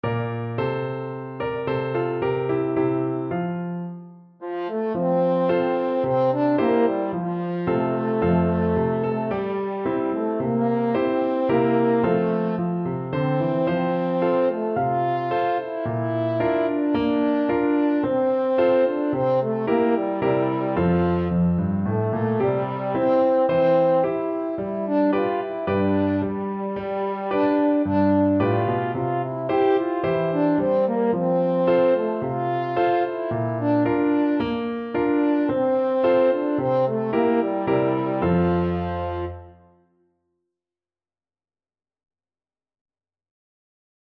Moderately fast =c.110
4/4 (View more 4/4 Music)
Classical (View more Classical French Horn Music)